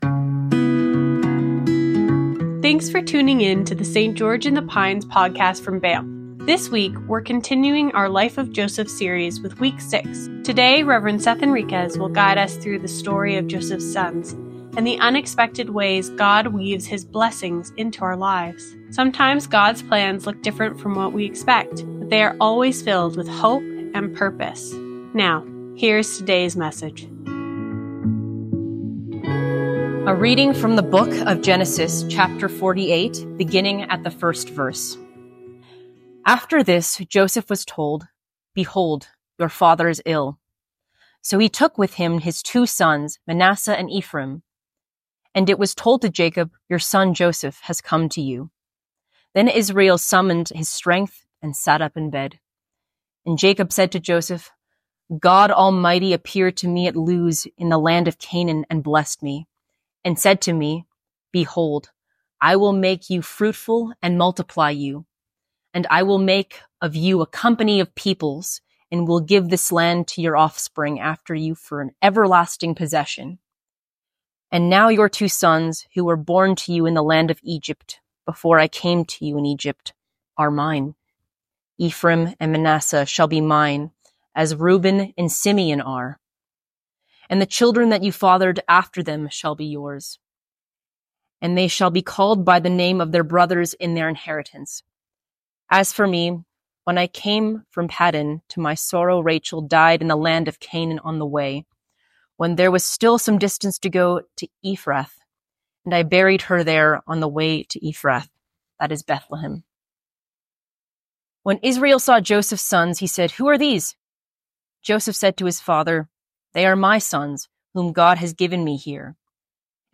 Sermons | St. George in the Pines Anglican Church